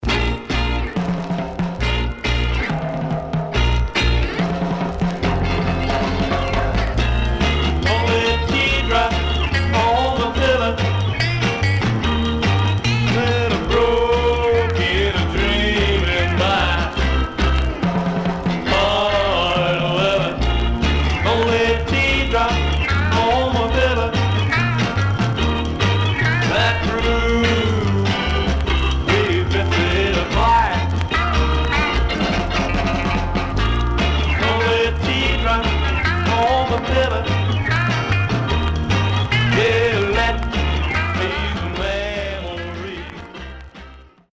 Surface noise/wear Stereo/mono Mono
Garage, 60's Punk